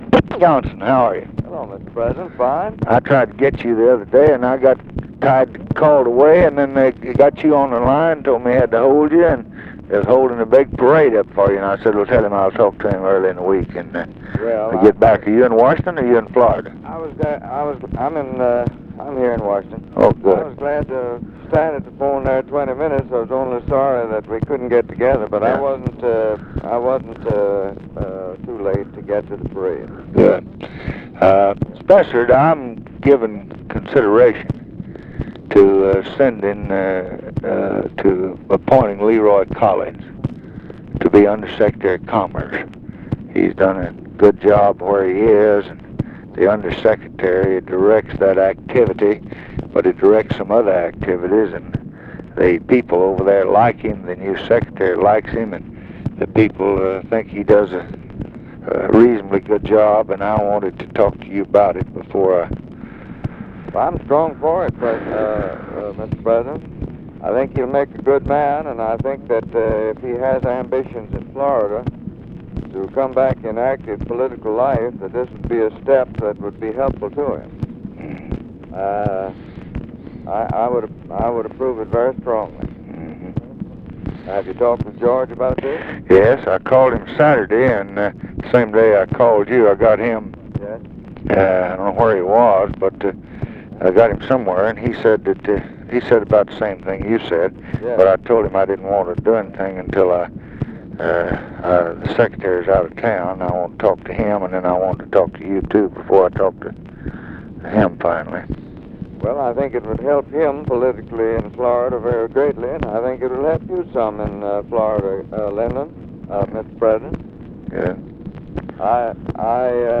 Conversation with SPESSARD HOLLAND, June 21, 1965
Secret White House Tapes